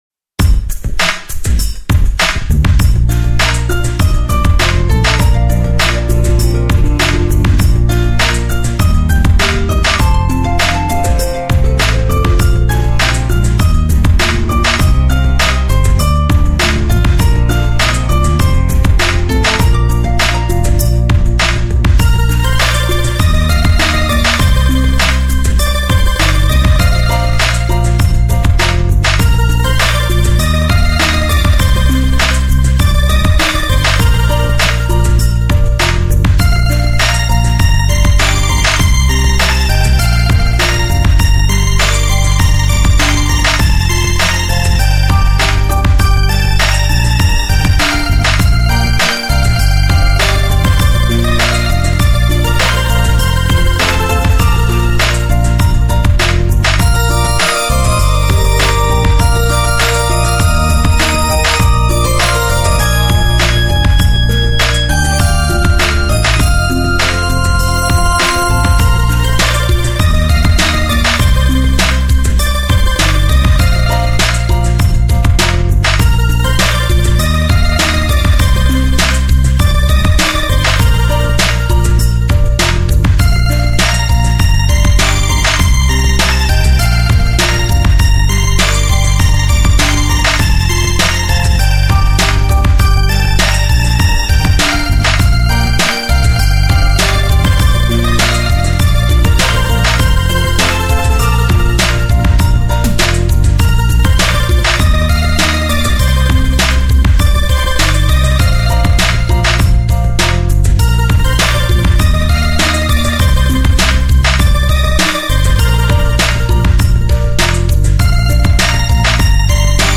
最纯净的清新音乐天堂.
纯净晶莹的乐曲 一如似水的岁月,